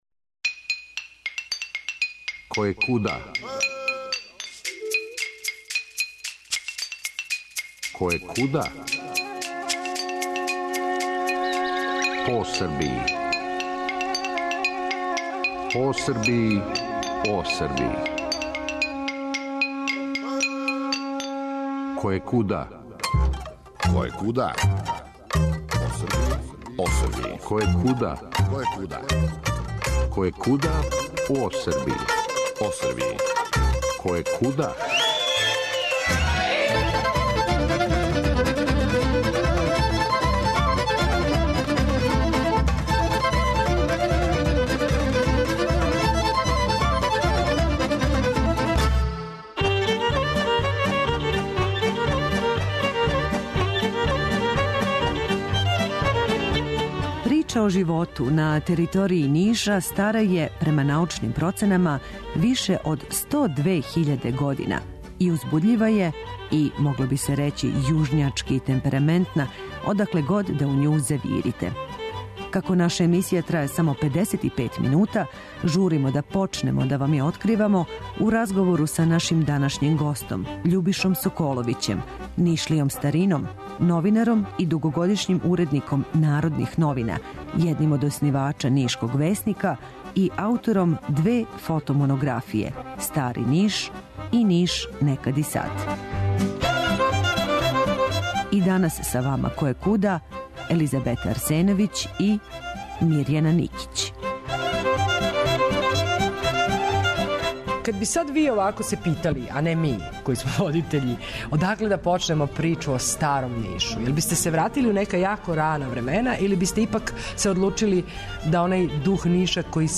Емисија Радио Београда 1